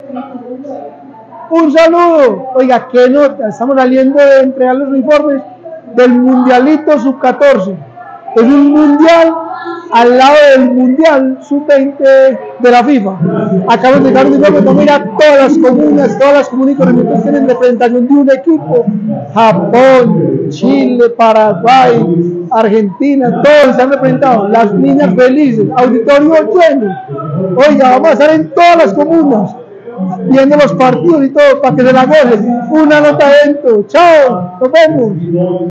Palabras de Eduardo Silva Meluk, Director del Inder